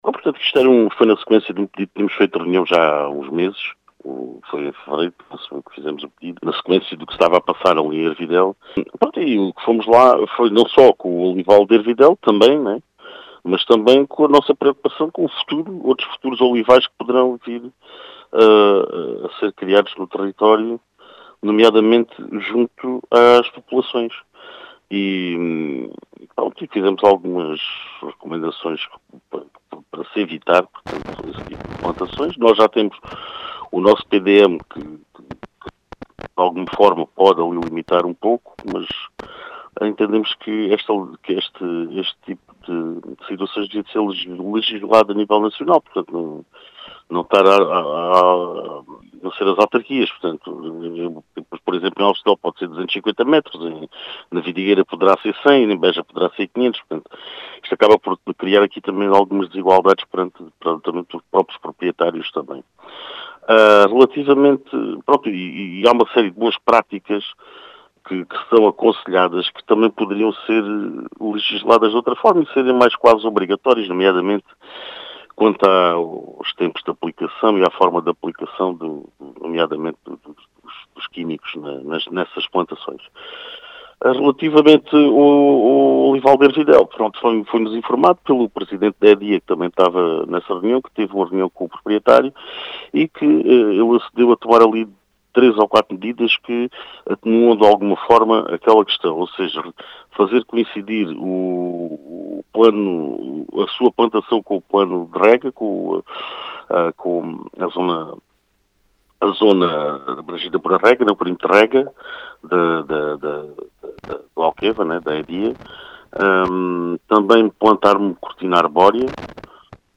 As explicações foram deixadas pelo presidente da Câmara Municipal de Aljustrel, Carlos Teles.